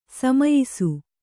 ♪ samayisu